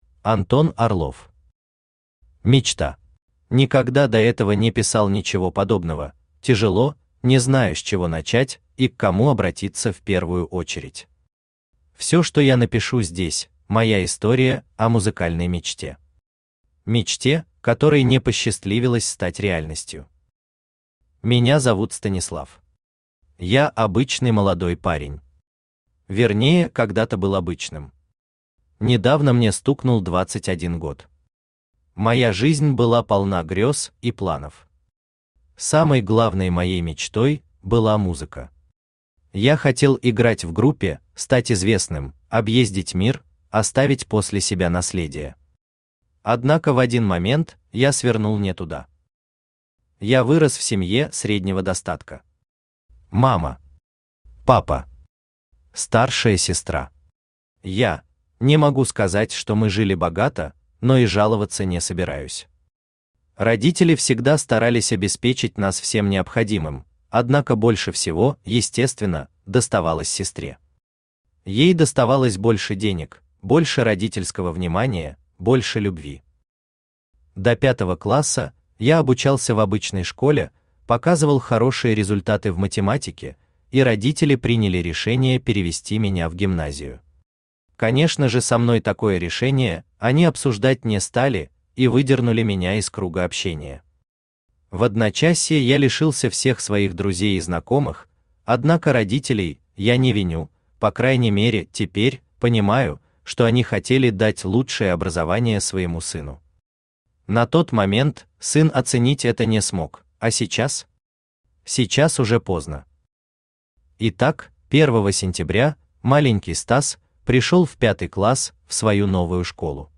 Аудиокнига Мечта | Библиотека аудиокниг
Aудиокнига Мечта Автор Антон Орлов Читает аудиокнигу Авточтец ЛитРес.